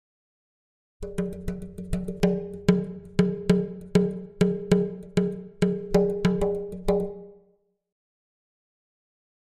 Drums Exotic Percussion Beat Version A